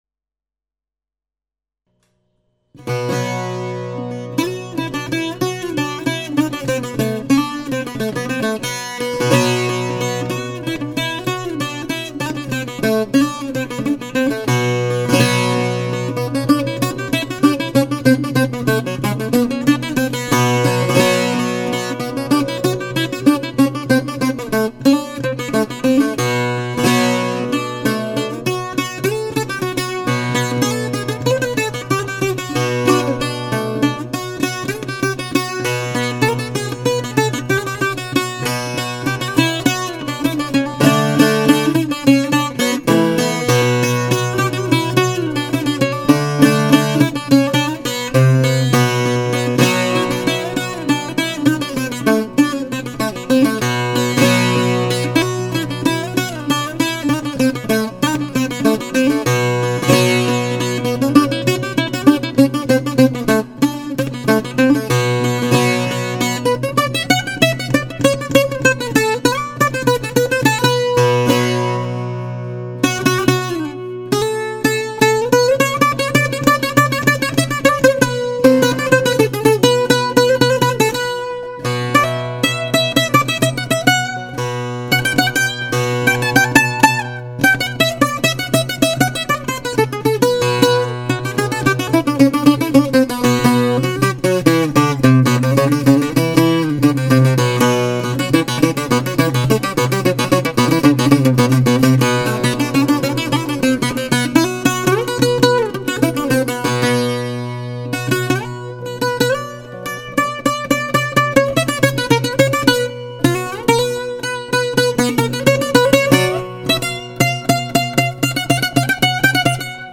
Τρίχορδη μπουζουκομάνα από μουριά με τα παρακάτω χαρακτηριστικά:
• Ξύλο σκάφους: μουριά
ξύλο Έλατο